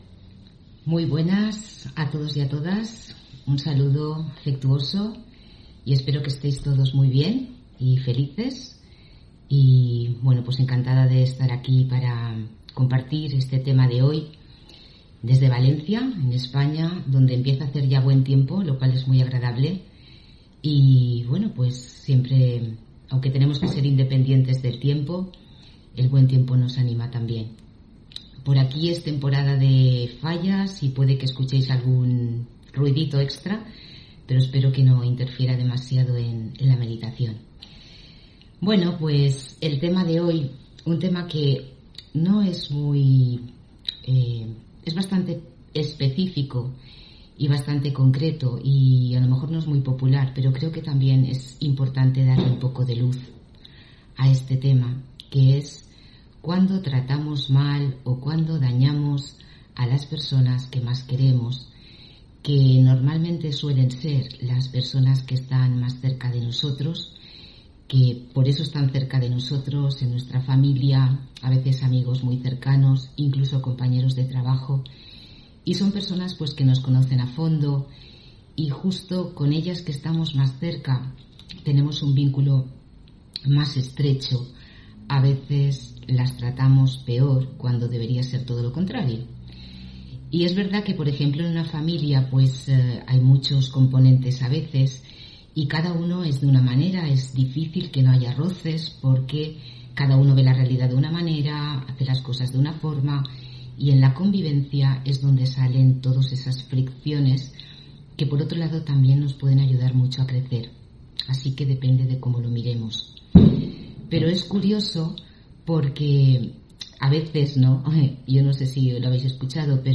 Meditación Raja Yoga y charla: Entrenamiento para lograr concentración (7 Marzo 2021) On ...